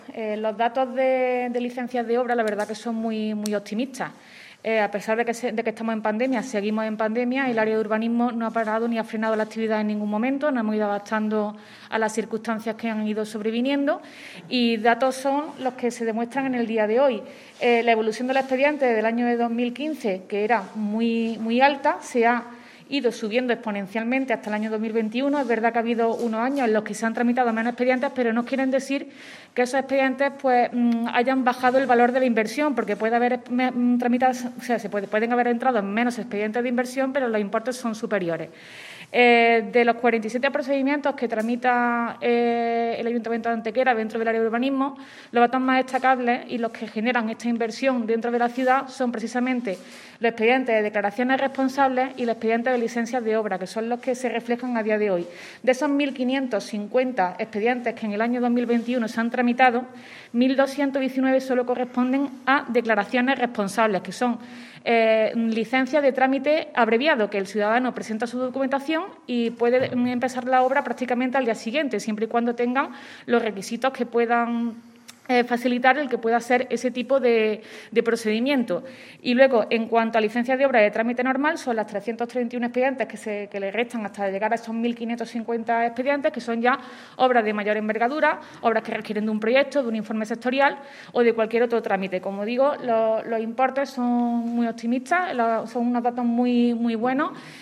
El alcalde de Antequera, Manolo Barón, y la teniente de alcalde delegada de Urbanismo, Teresa Molina, han informado en rueda de prensa del balance del número de expedientes de proyectos de licencias de obra –y su importe equivalente– presentados durante el pasado año 2021, ejercicio que supone ser el de la definitiva recuperación económica tras el negativo impacto de la pandemia del coronavirus en 2020.
Cortes de voz